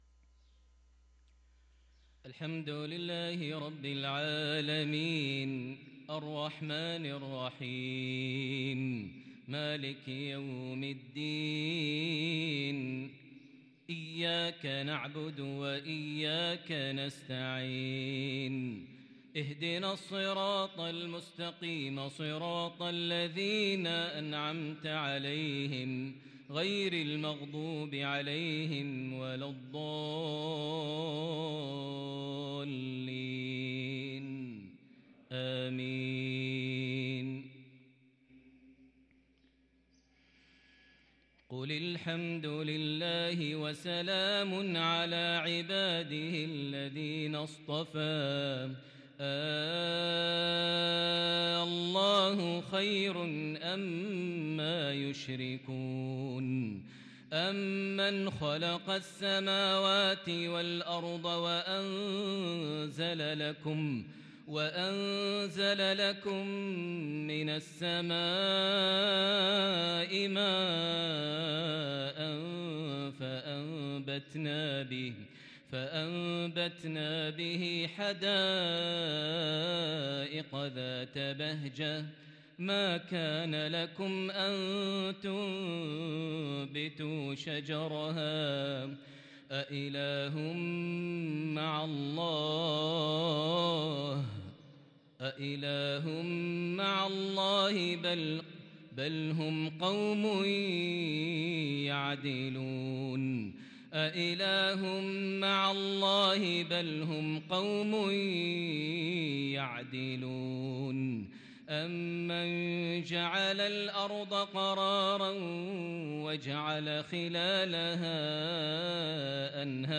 صلاة المغرب للقارئ ماهر المعيقلي 12 جمادي الآخر 1444 هـ
تِلَاوَات الْحَرَمَيْن .